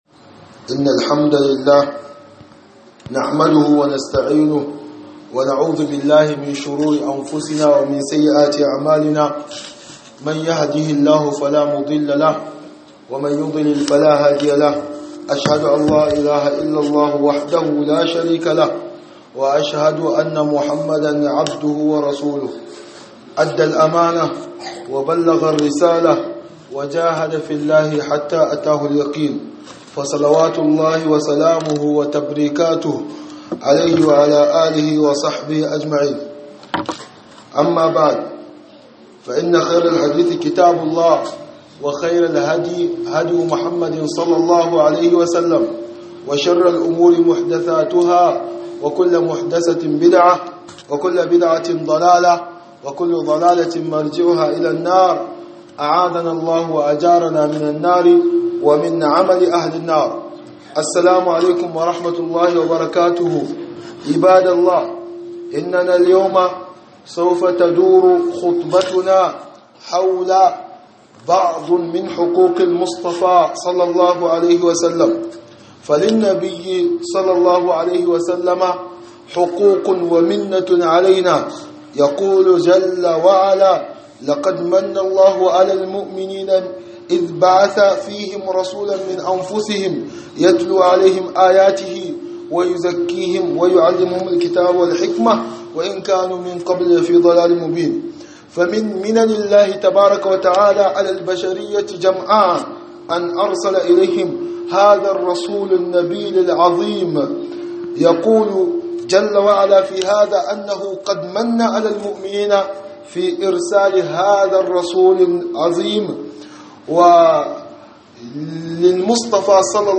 خطبة بعنوان من حقوق المصطفى صلى الله عليه وسلم